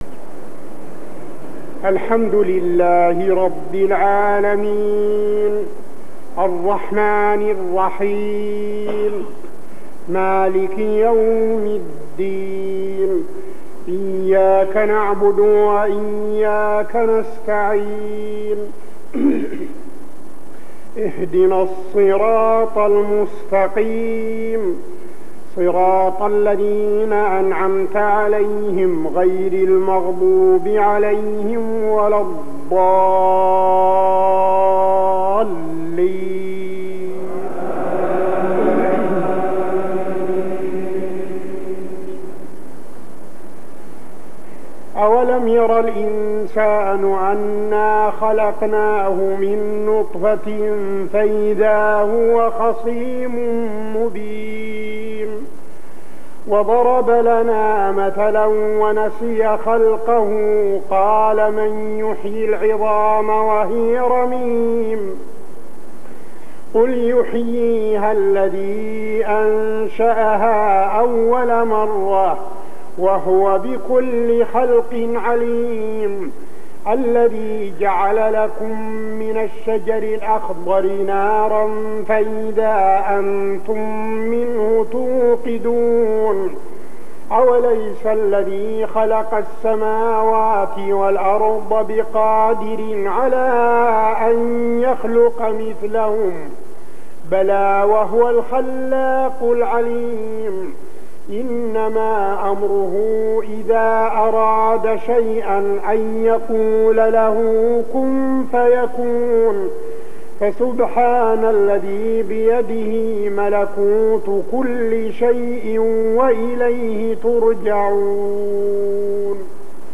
صلاة العشاء ( تقريباً عام 1401هـ ) من سورتي يسٓ 77-83 و الصافات 171-182 | Isha prayer Surah Yaseen and As-Saaffaat > 1401 🕌 > الفروض - تلاوات الحرمين